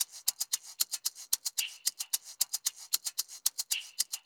DWS SHAKER.wav